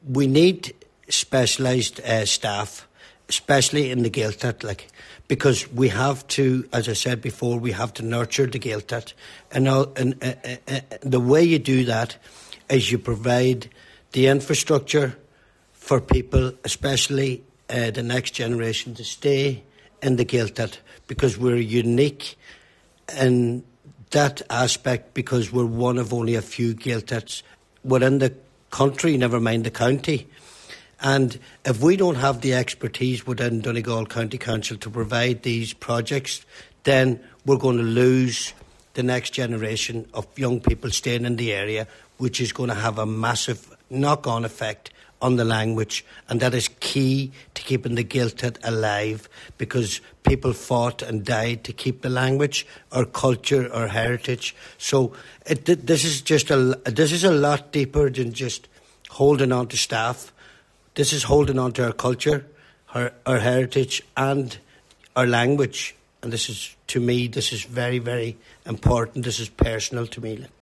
That’s according to Cllr Dennis McGee, who was speaking at a Plenary Meeting at County House in Lifford.